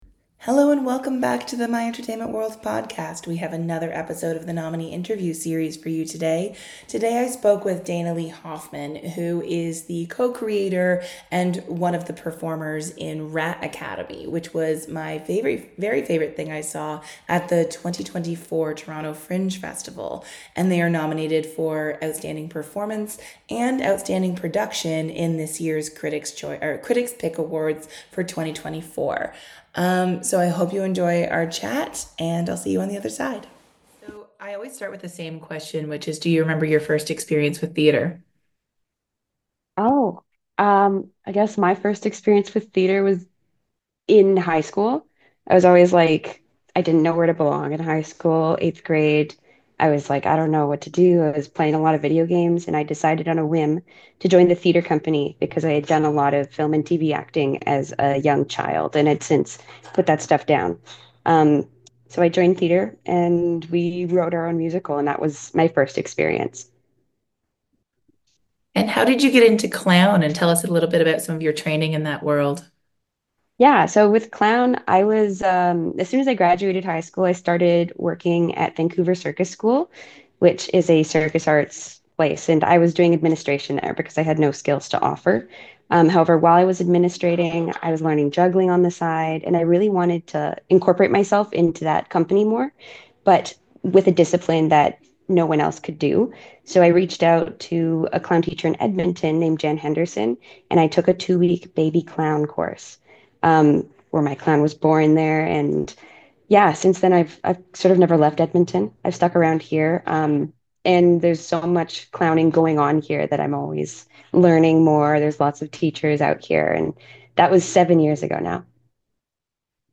Before we announce the winners of our 2024 Critics’ Pick Awards, we’re proud to present our annual Nominee Interview Series.